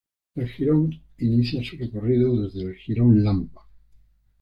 re‧co‧rri‧do
/rekoˈrido/